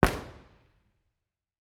IR_EigenmikeHHB2_processed.wav